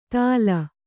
♪ tāla